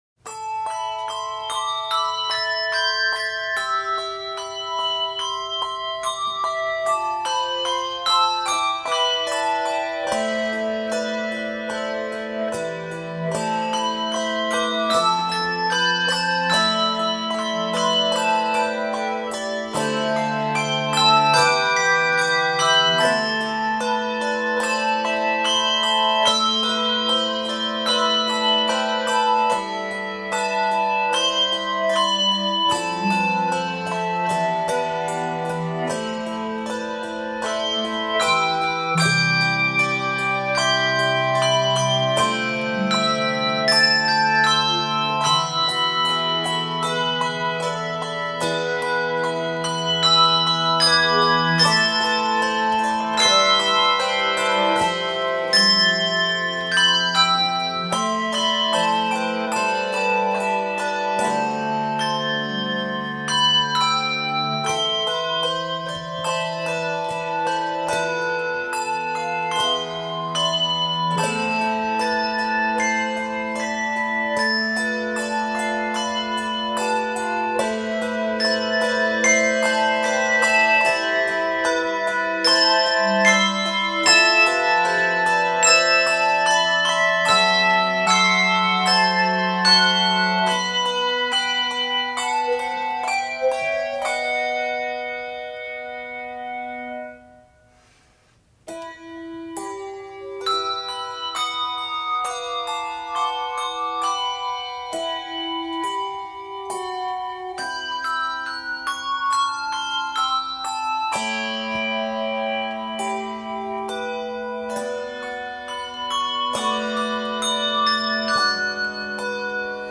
Composer: African Ameircan Spiritual